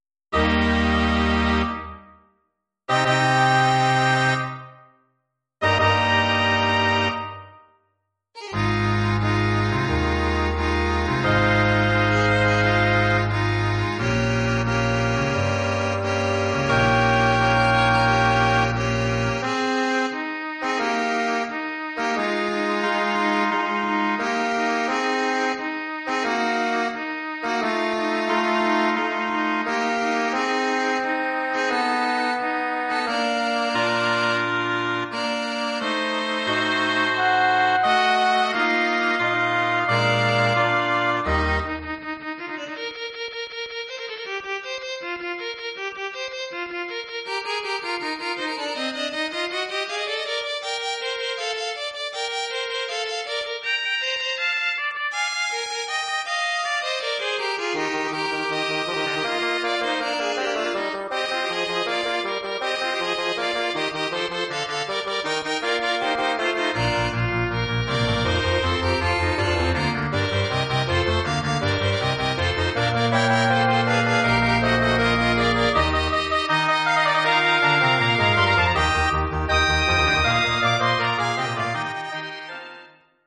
Bearbeitung für Nonett
arrangement for nonet